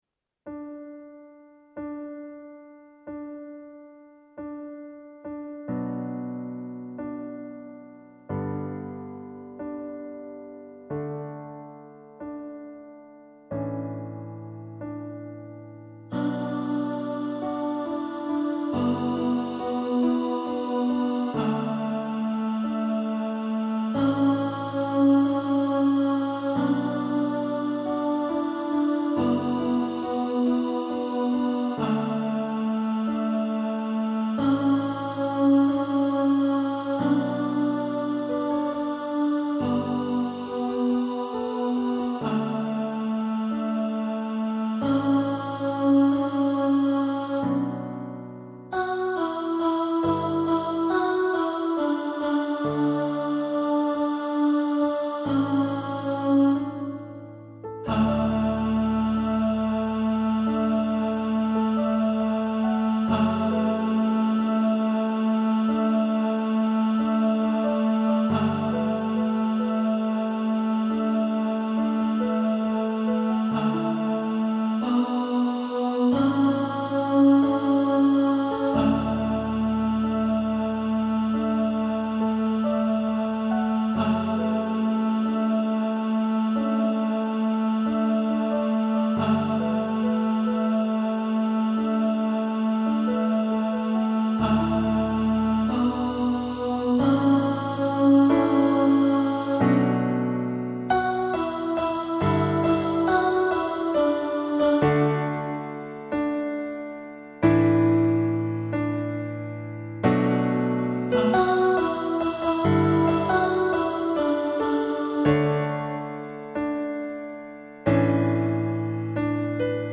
Say-Something-Alto.mp3